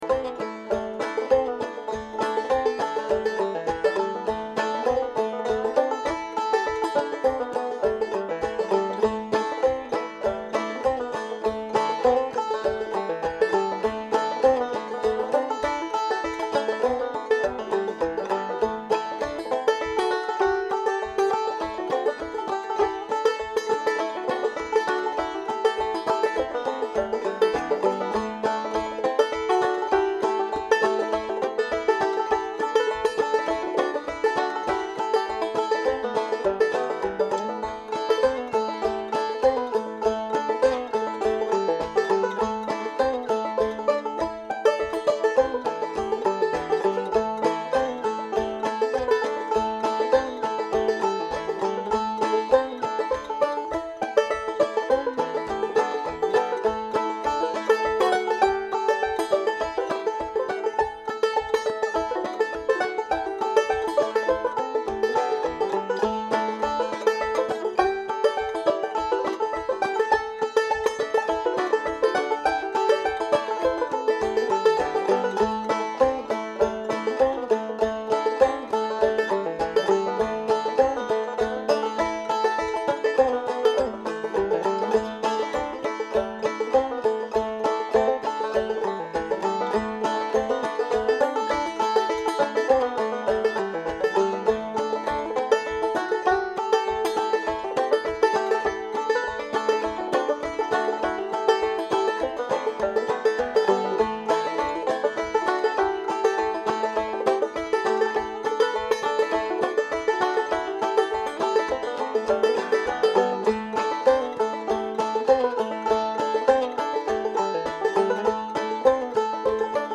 Notes: This week we are going to work with a traditional tuning used a lot by clawhammer pickers for modal tunes in G and A. It's called Sawmill tuning, or Mountain Minor, and it it basically open G tuning with the 2nd string tunes up to C (gDGCD). The tune is Greasy Coat, generally fiddled in the key of A, so the banjo is capoed on the 2nd fret.
I am using a slightly different tuning, with the 2nd string lowered to A (gDGAD).